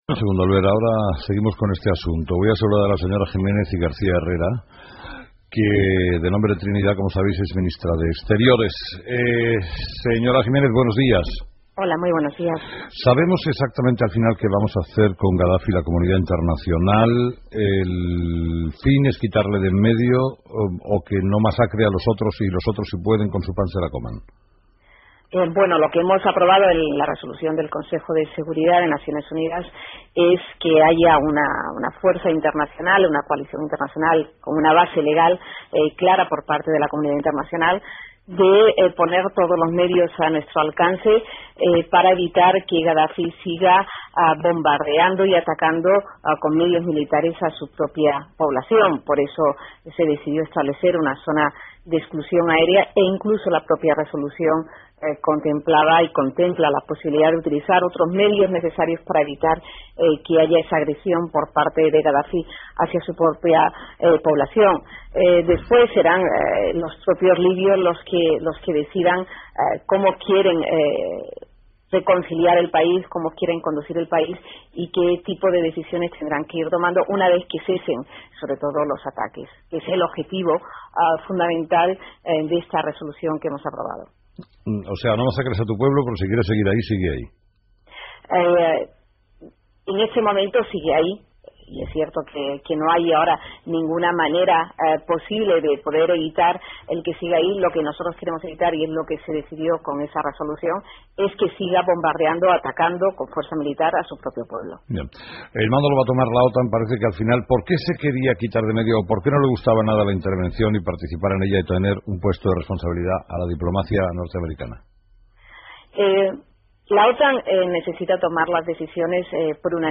Entrevista a Trinidad Jiménez....
Entrevistado: "Trinidad Jiménez"
La ministra de Asuntos Exteriores, Trinidad Jiménez, ha sido entrevistada esta mañana en el programa Herrera en la Onda y ha explicado la posición de España y de otros países, como Francia, en el conflicto de Libia.